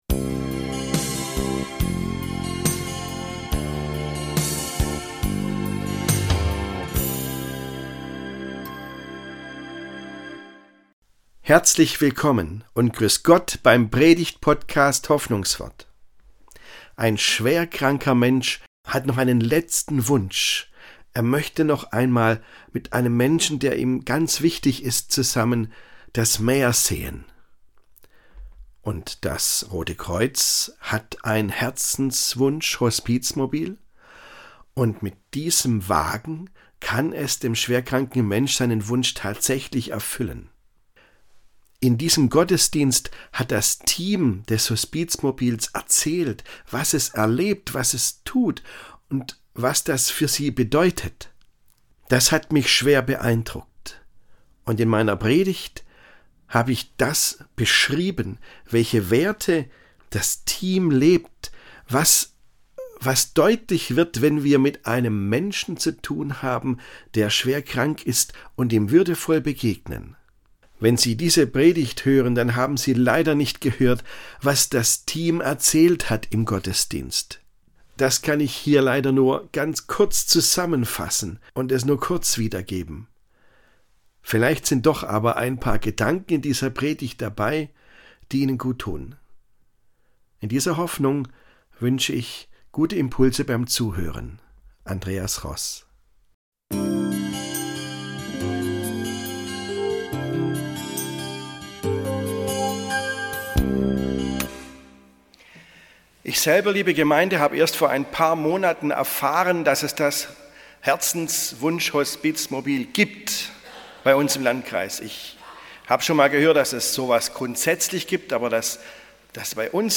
Ein letzter Wunsch ~ Hoffnungswort - Predigten